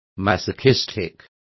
Complete with pronunciation of the translation of masochistic.